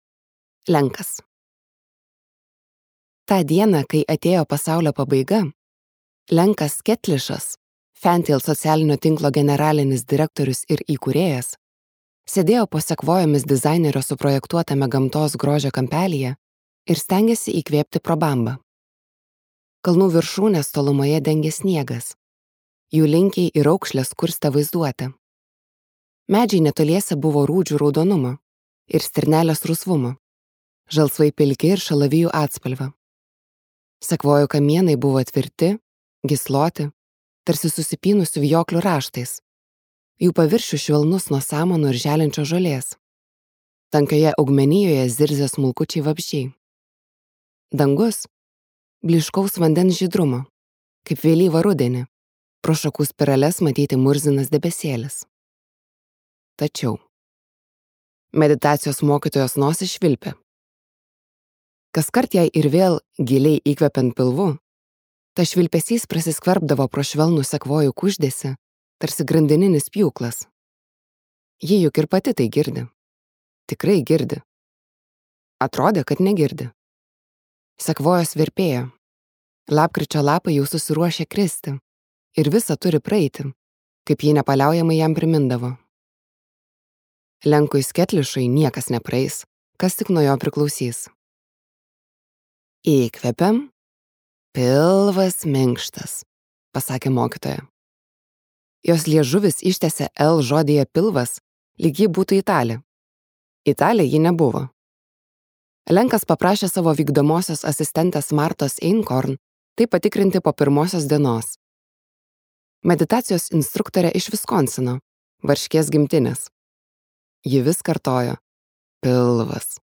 Ateitis | Audioknygos | baltos lankos